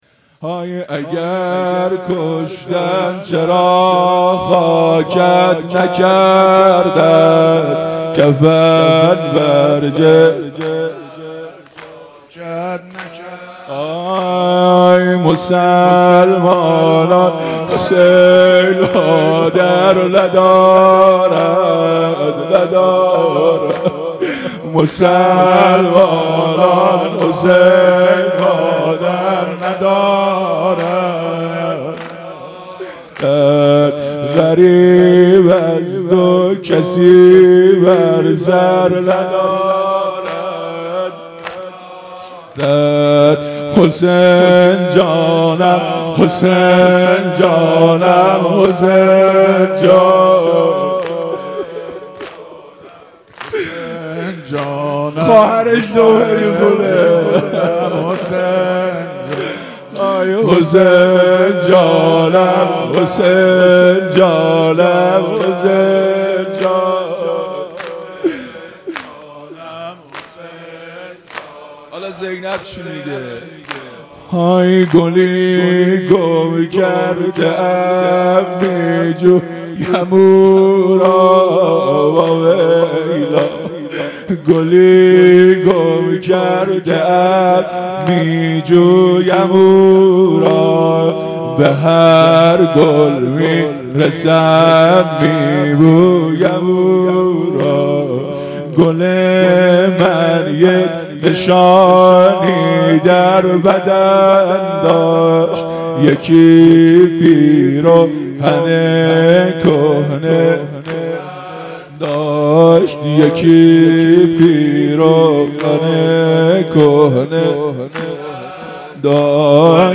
زمینه | اگر کشتن چرا خاکت نکردن مداح
مراسم عزاداری محرم الحرام ۱۴۴۳_شب یازدهم